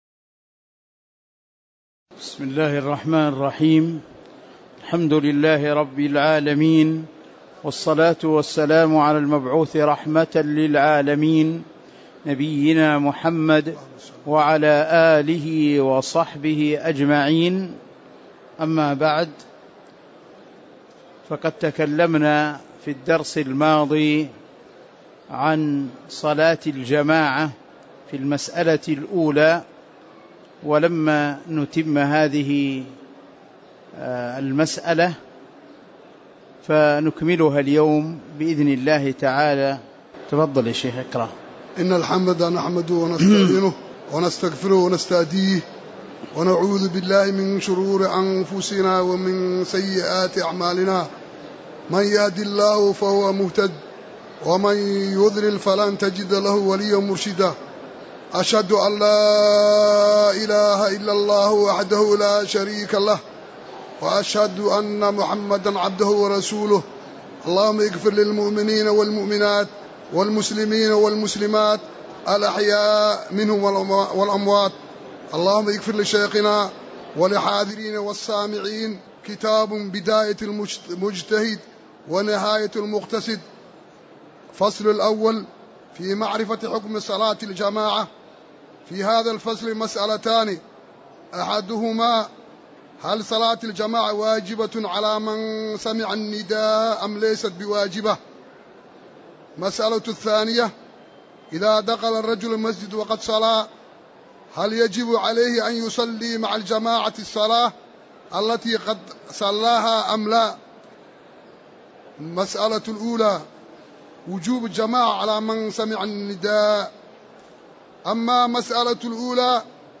تاريخ النشر ٣٠ جمادى الآخرة ١٤٤١ هـ المكان: المسجد النبوي الشيخ